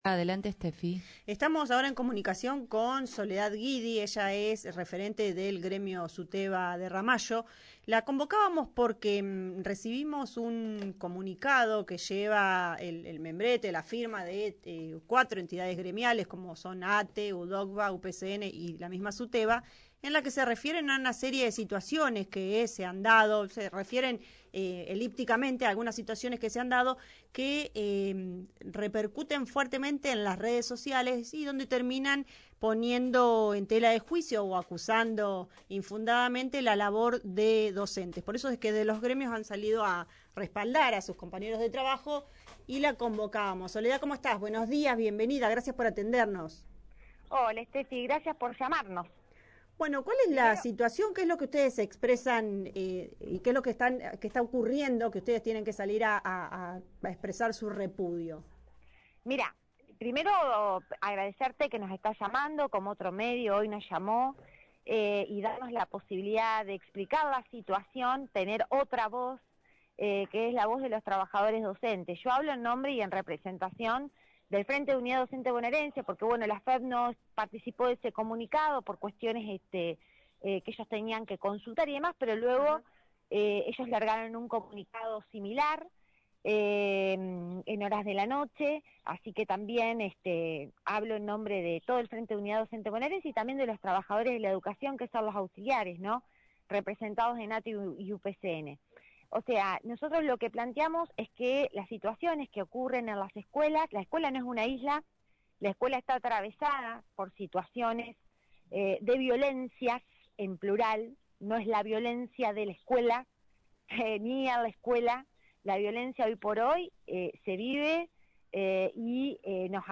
Durante una entrevista en el programa Estación Macondo de Radio Ramallo